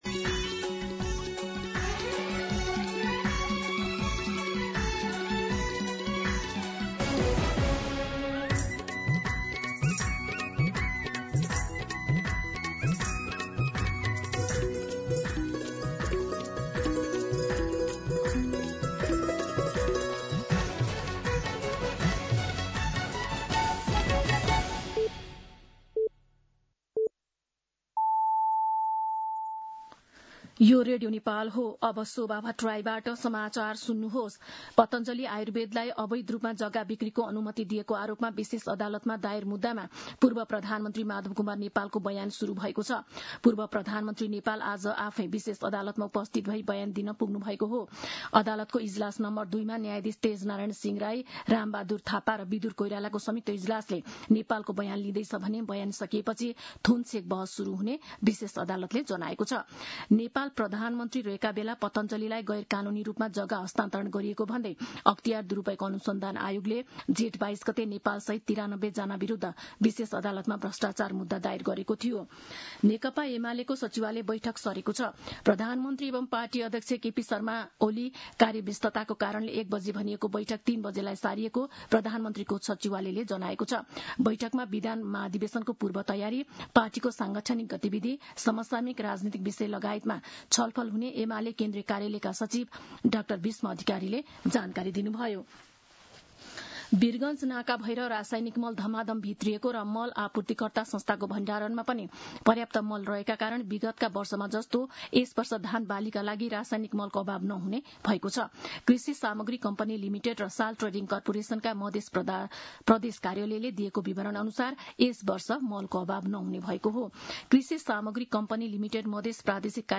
दिउँसो १ बजेको नेपाली समाचार : ११ असार , २०८२